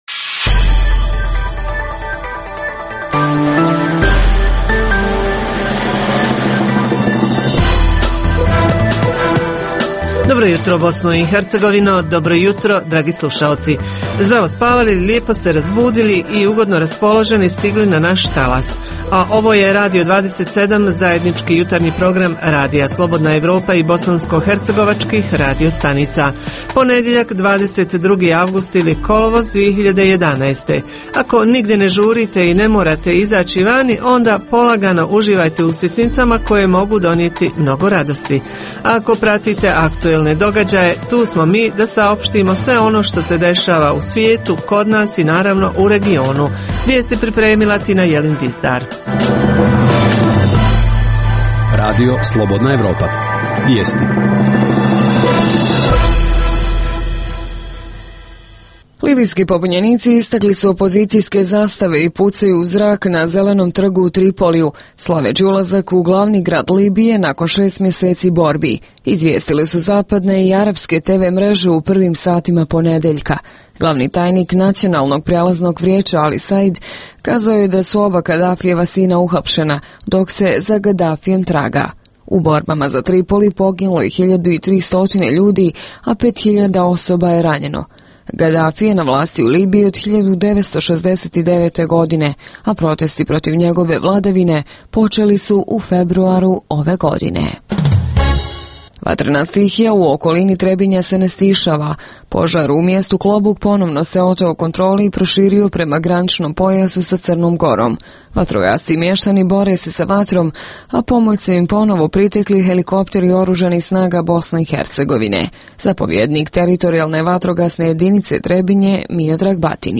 Između ostalog reporteri javljaju o problemima s pitkom vodom u Zavidovićima, o projektu Fondacije „Mozaik“ namijenjenog razvoju mjesnih zajednica na primjeru Cazina, te o tzv. učenju na daljinu – kako koriste ovu metodu Banjalučani? Rubrika posvećena povratnicima, vodi nas na Vlašićki plato, u selo Gornji Korićani. Emisije vijesti su na početku, na sredini i na kraju jutarnjeg programa. Redovni sadržaji jutarnjeg programa za BiH su i vijesti i muzika.